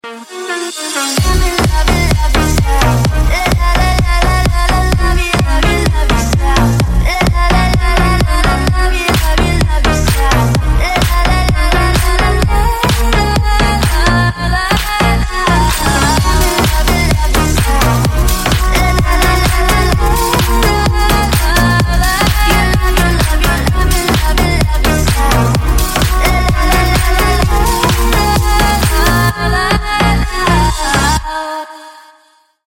Клубные Рингтоны » # Громкие Рингтоны С Басами
Танцевальные Рингтоны